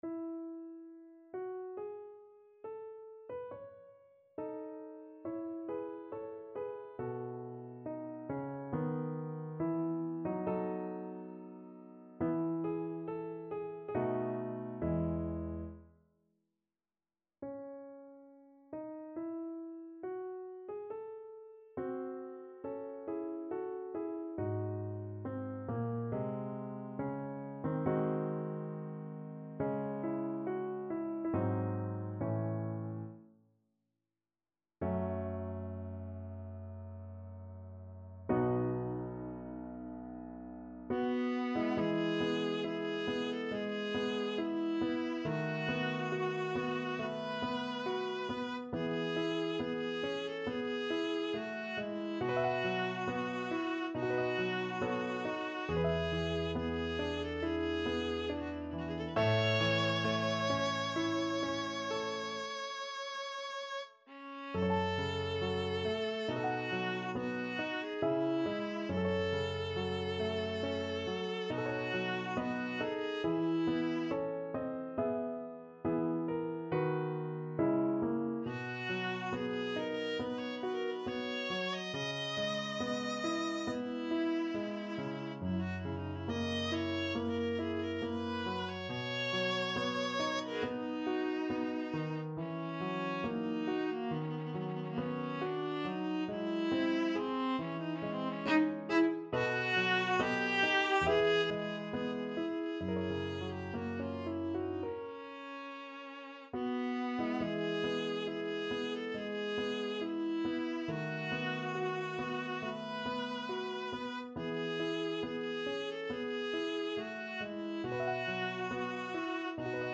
Viola
E major (Sounding Pitch) (View more E major Music for Viola )
Larghetto (=80) =69
Classical (View more Classical Viola Music)
chopin_piano_con1_2nd_mvt_VLA.mp3